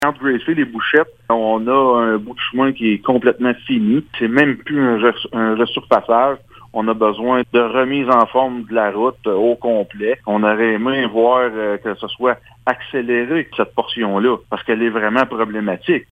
Le maire de Gracefield, Mathieu Caron, précise qu’une portion de la route 105 entre sa Ville et la Municipalité de Bouchette aurait dû être priorisée :